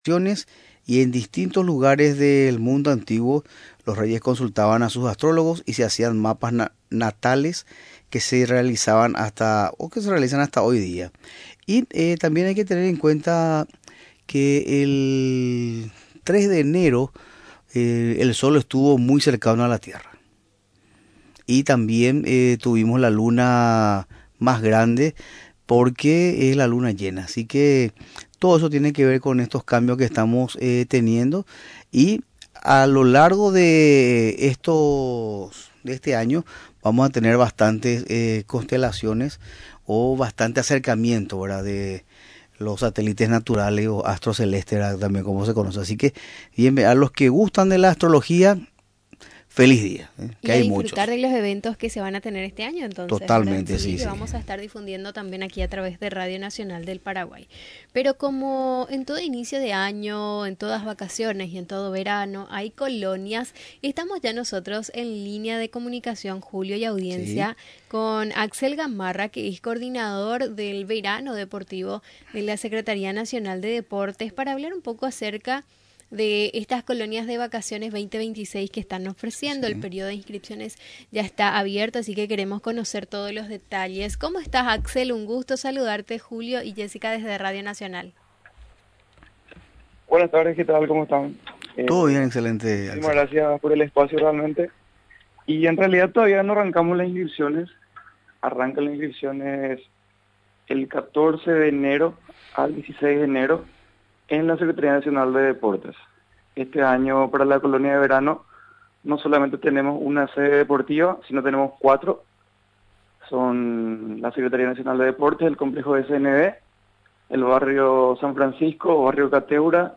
Durante la entrevista en Radio Nacional del Paraguay, recordó que las sedes de las actividades serán en el